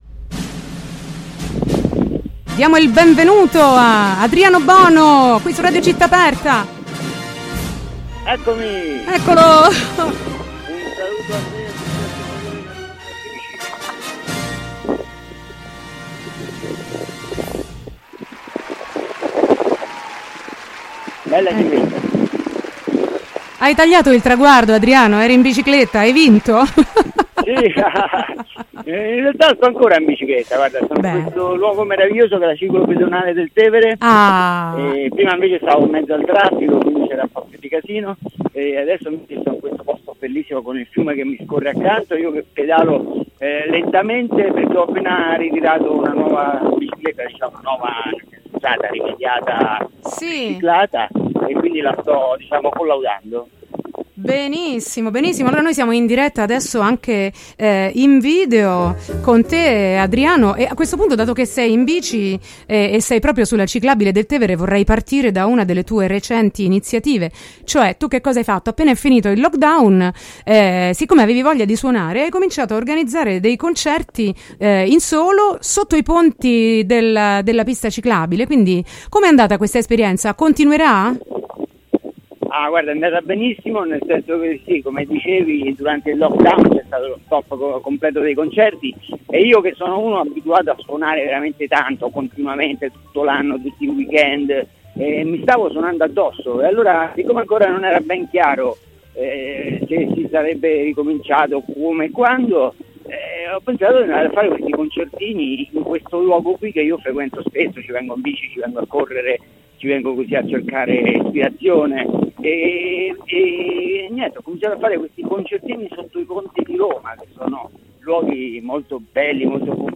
Lavorare gratis? "Se, lallero"! Intervista
I brani andati in onda nel corso dell’intervista: